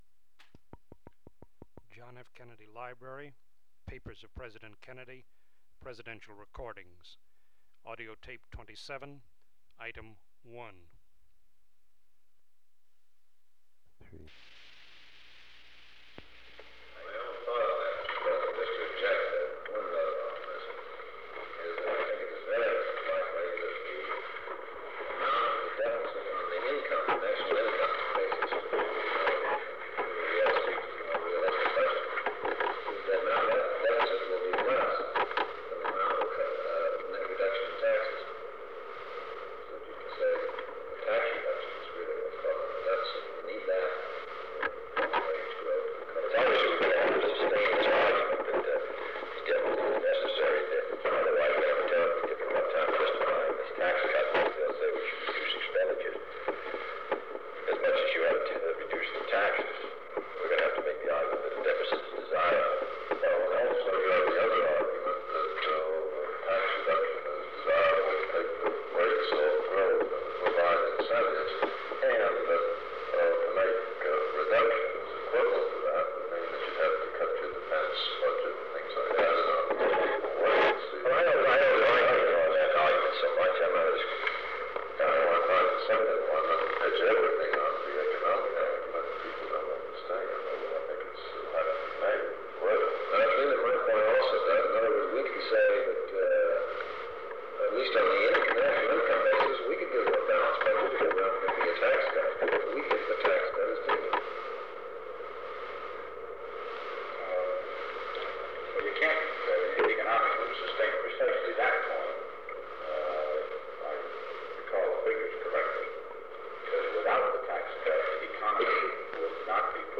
Meeting on the Budget and Tax Cut Proposal
Secret White House Tapes | John F. Kennedy Presidency Meeting on the Budget and Tax Cut Proposal Rewind 10 seconds Play/Pause Fast-forward 10 seconds 0:00 Download audio Previous Meetings: Tape 121/A57.